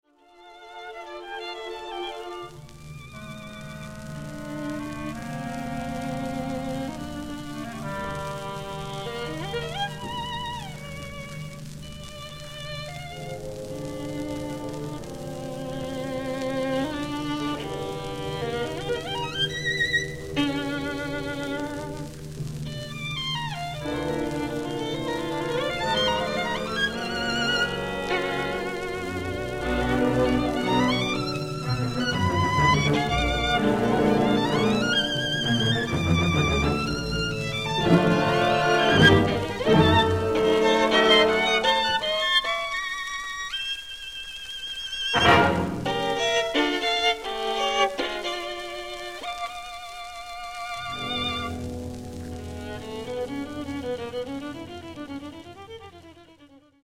(1945年11月21日ロンドン, アビー・ロード第1スタジオ録音)